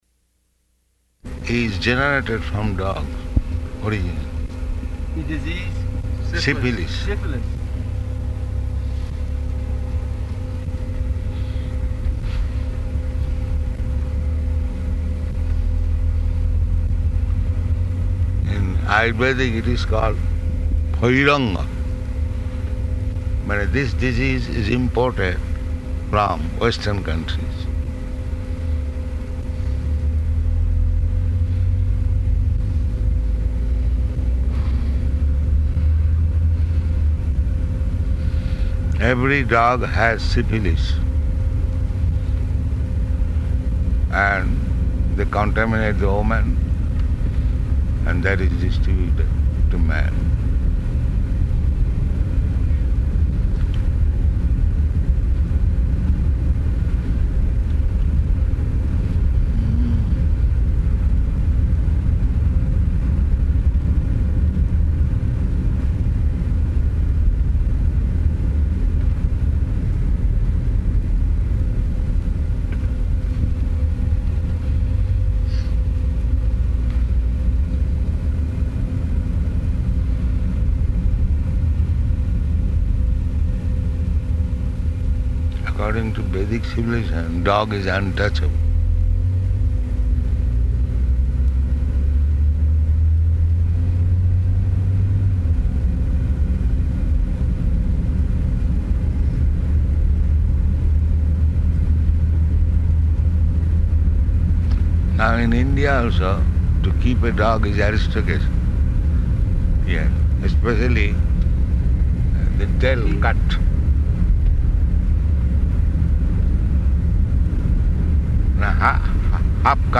Conversation
Conversation --:-- --:-- Type: Conversation Dated: October 15th 1975 Location: Johannesburg Audio file: 751015R1.JOH.mp3 [in car] Prabhupāda: ...is generated from dog originally.